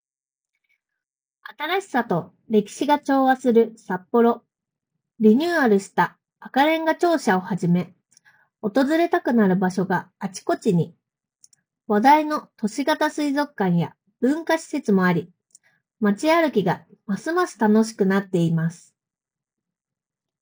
本製品はENCノイズキャンセリング対応のデュアルマイクを内蔵しており、イヤホン越しにハンズフリー通話を行うことが可能です。
▼ACEFAST ACEFIT Proで収録した音声
iPhone 16 Proで撮影した動画と、イヤホン内蔵マイクで収録した音声を聴き比べてみると、若干くぐもった声に聴こえるものの、内蔵マイクでは周囲の環境ノイズ（空調音、本に触れる音）などが効果的に低減されており、装着者の声のみを明瞭に拾い上げることができています。